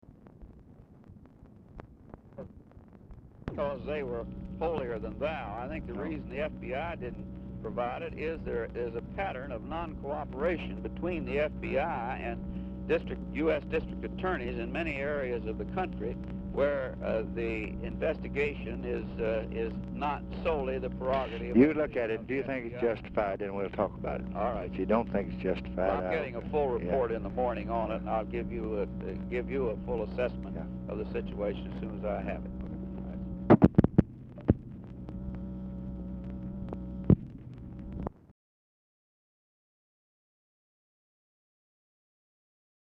Telephone conversation # 11349, sound recording, LBJ and HENRY "JOE" FOWLER
RECORDING STARTS AFTER CONVERSATION HAS BEGUN; CONTINUES FROM PREVIOUS RECORDING
Format Dictation belt
Oval Office or unknown location